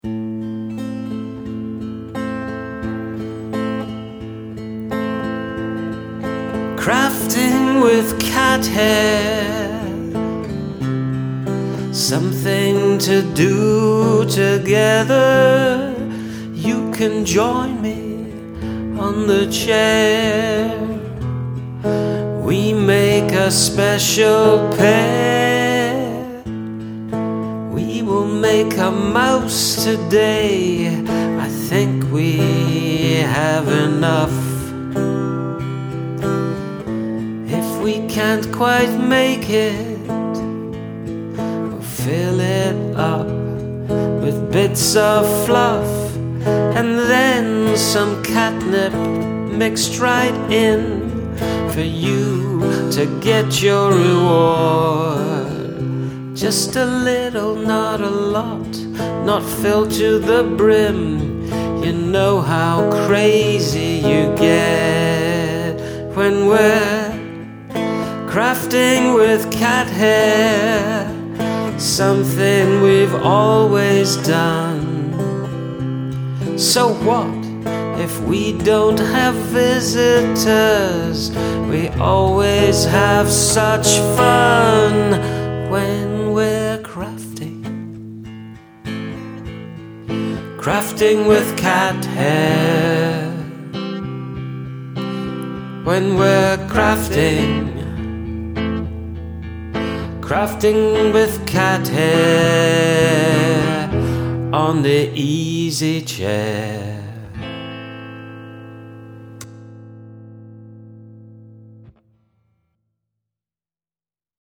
Ooh, after the fun of the opening, that second chorus gives it a much darker feeling.